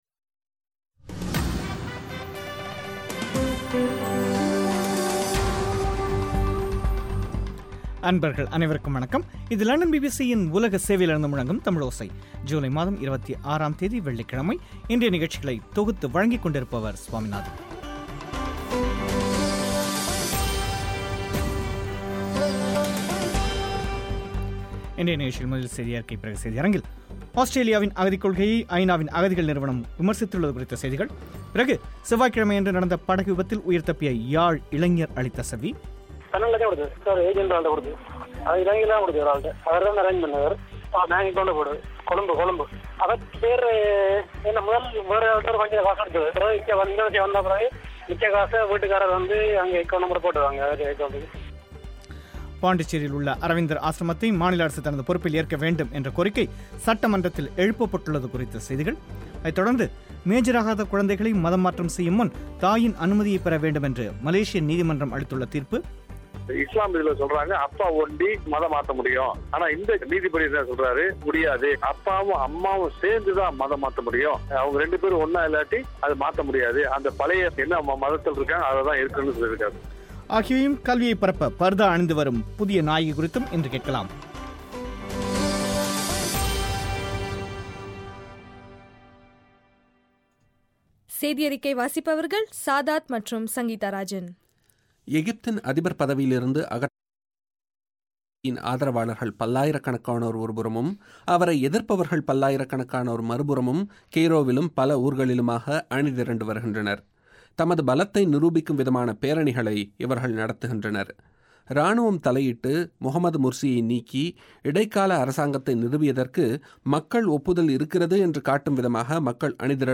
ஜூலை மாதம் 26 ஆம் தேதி வெள்ளிக் கிழமை நிகழ்ச்சியில், ஆஸ்திரேலியாவின் அகதிக் கொள்கையை ஐ நா விமர்சித்துள்ளது குறித்தும், சமீபத்தில் விபத்துக்குள்ளான படகில் பயணம் செய்த இலங்கையர்கள் அளித்த செவ்விகளும் இடம்பெறும்.